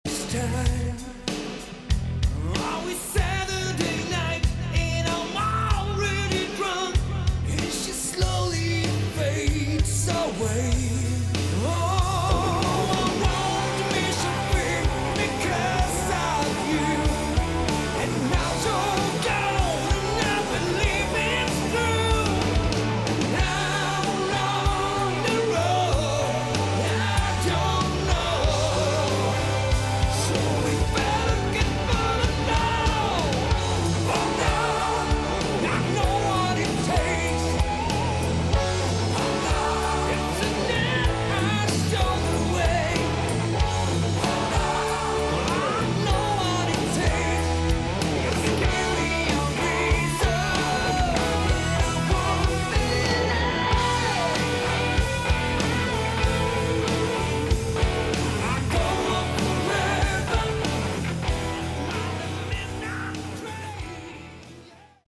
Category: AOR / Melodic Rock
Vocals, Guitars
Bass
Drums
Keyboards